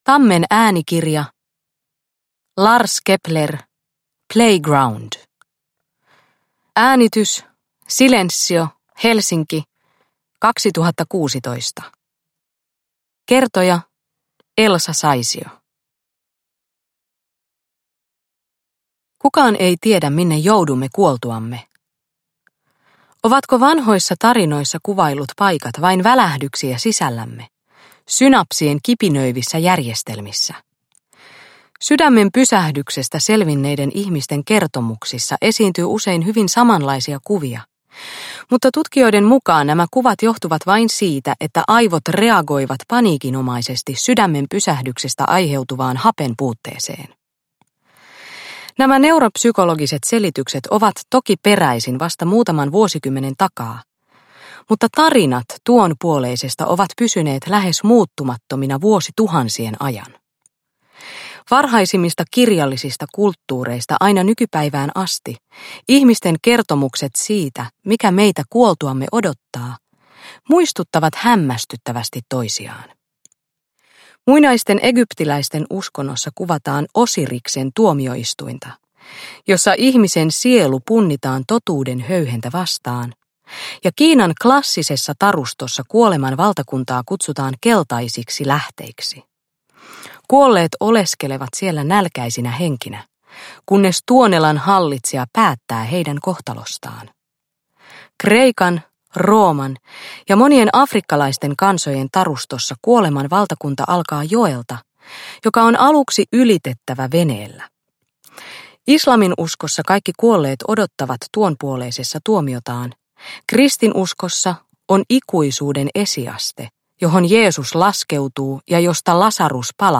Playground – Ljudbok – Laddas ner